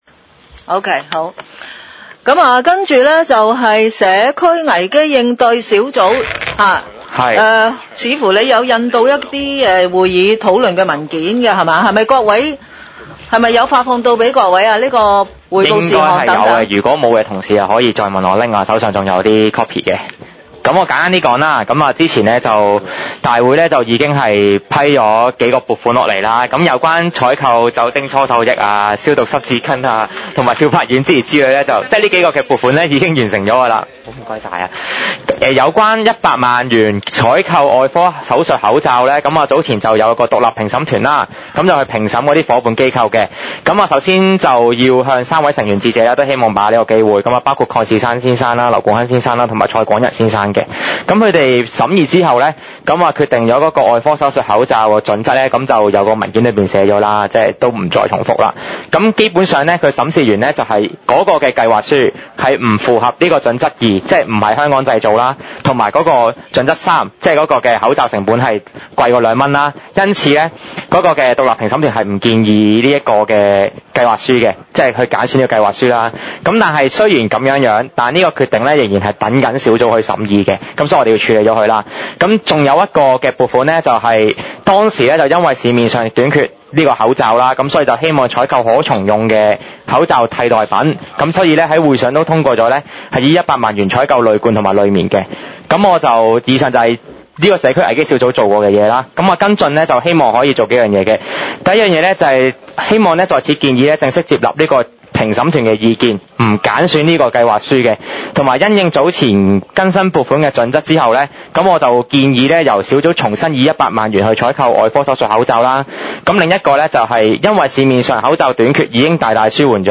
區議會大會的錄音記錄
屯門區議會會議室